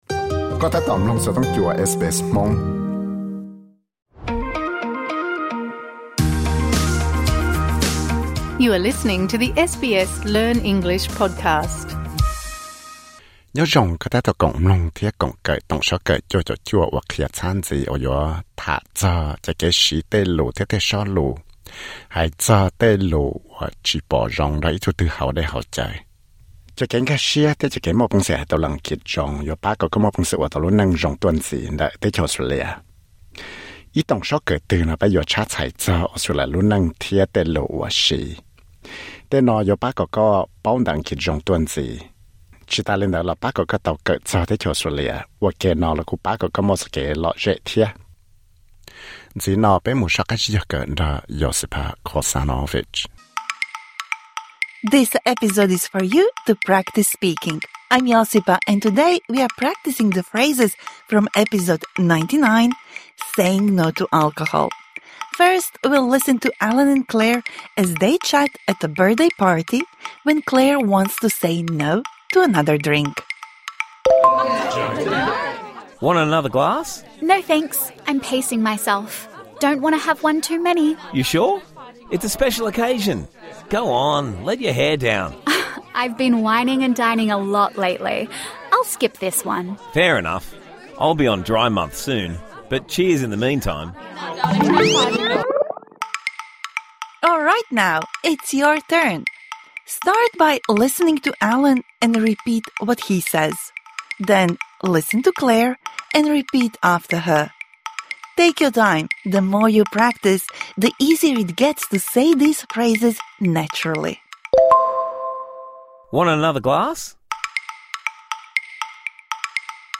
voiced the characters of Allan and Claire